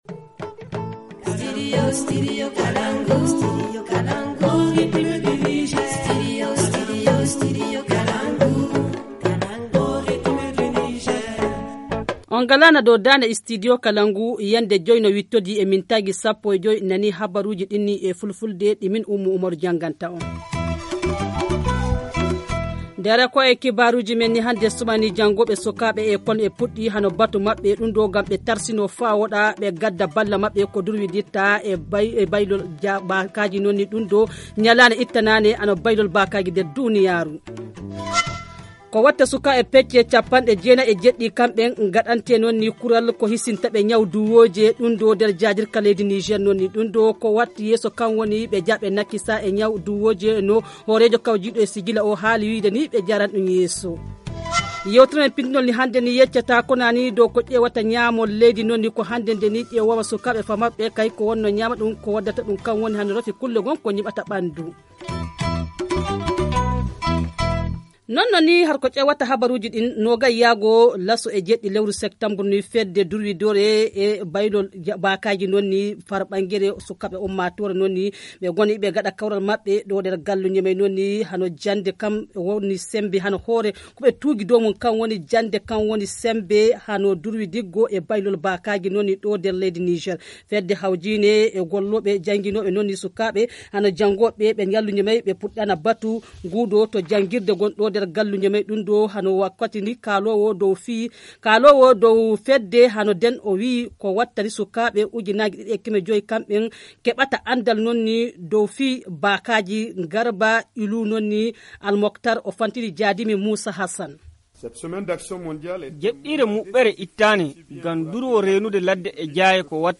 Le journal du 20 septembre 2019 - Studio Kalangou - Au rythme du Niger